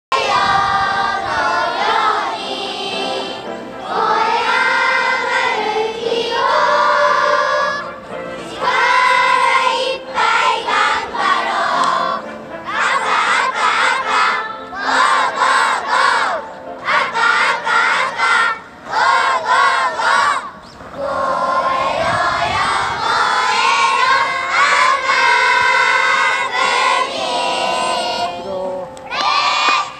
今日の全体練習は、開閉会式と応援合戦でした。
開閉会式の練習の後、紅白が互いに向き合い、応援歌を元気いっぱい歌いました。
運動会応援歌.WAV